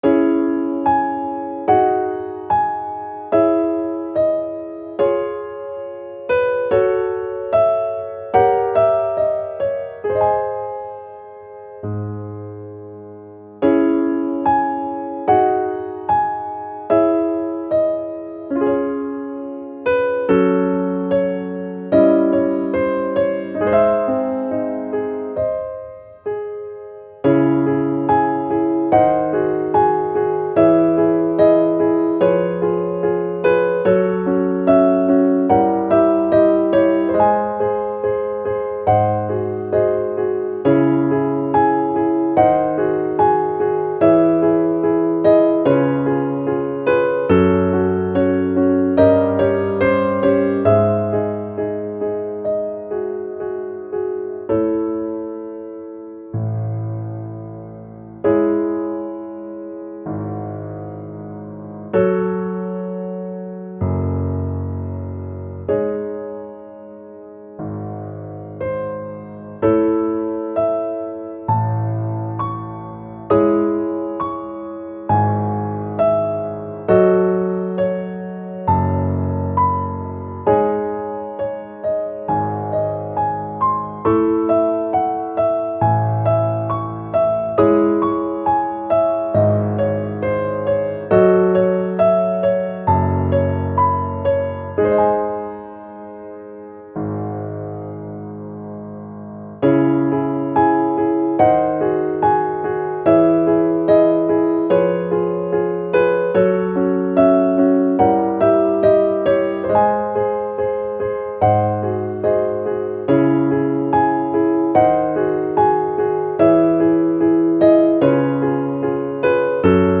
ogg(L) - 孤独感 迷い 不安